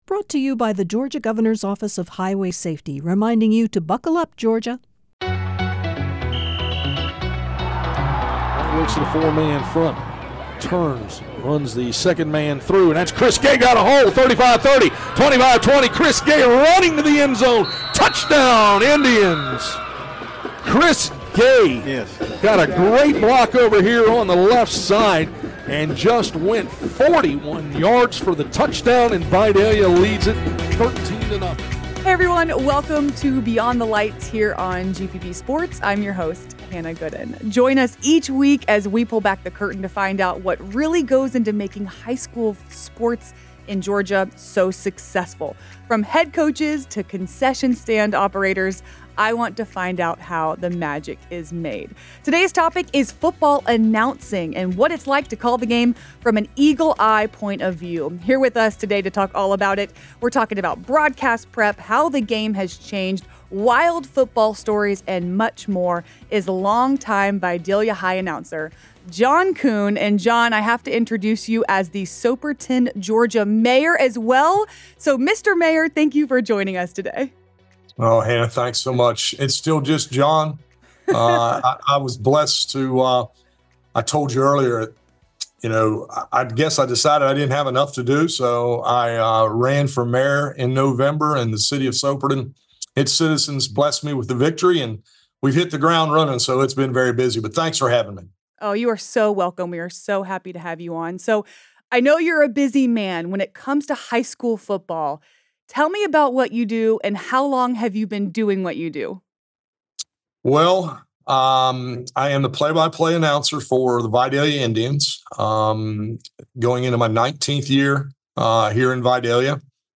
In this episode, we sit down with John Koon, a veteran play-by-play announcer (and mayor of Soperton, Georgia!) With nearly 43 years of broadcasting experience, John shares his unique journey, from his early days at Treutlen High School to becoming the voice of the Vidalia Indians. Discover the highs and lows of calling games, the evolution of high school sports, and the passion that drives him.